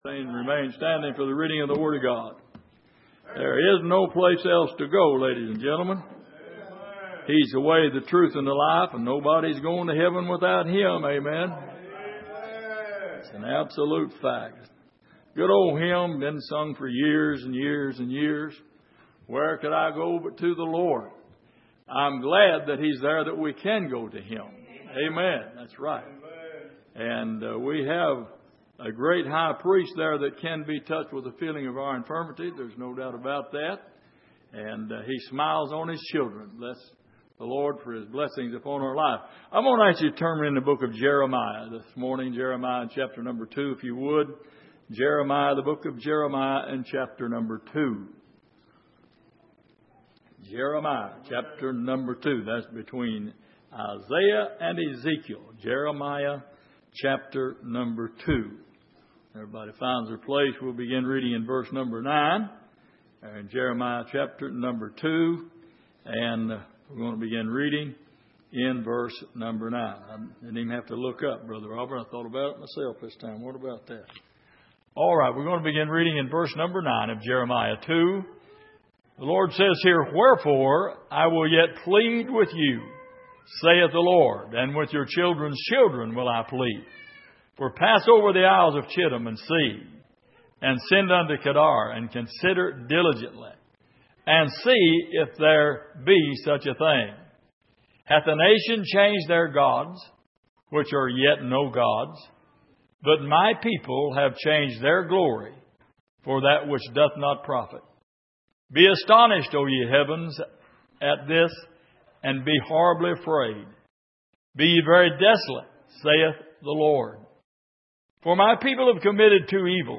Jeremiah 2:7-13 Service: Sunday Morning Has Your Water Run Out?